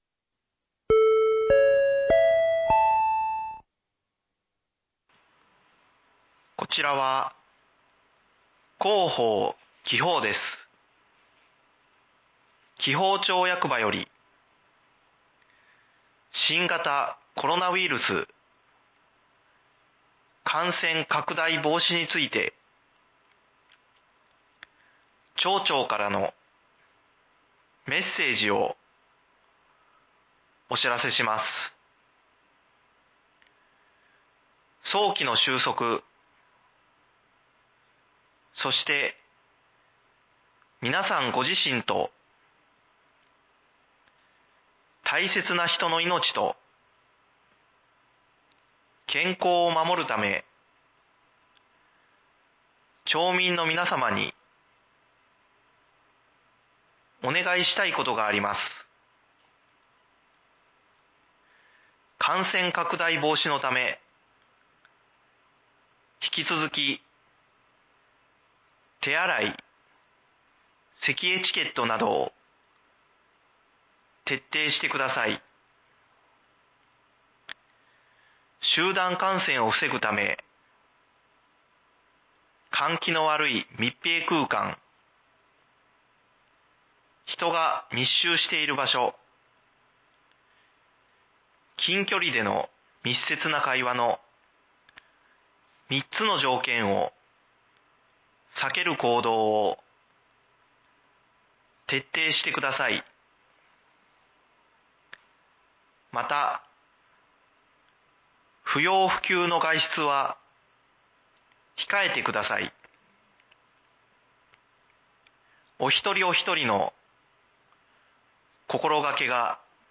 町長からのメッセージをお知らせいたします。